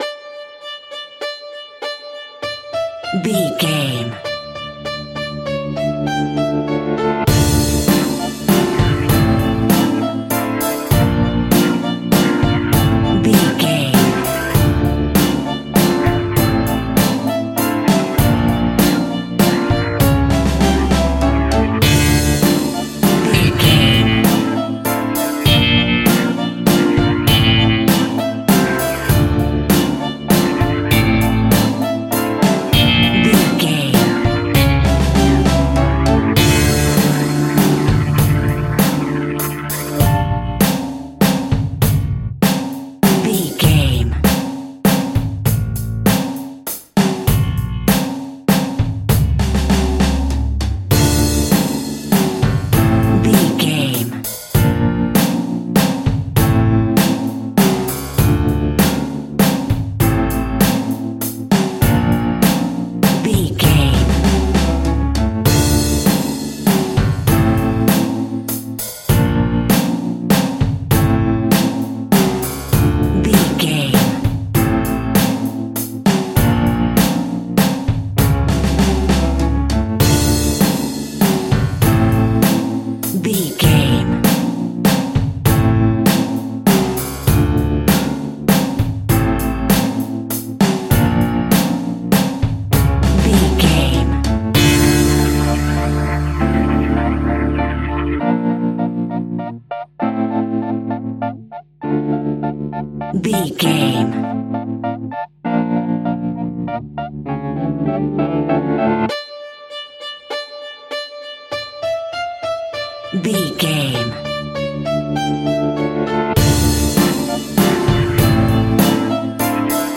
Aeolian/Minor
tension
ominous
eerie
electric guitar
violin
piano
strings
bass guitar
drums
percussion
horror music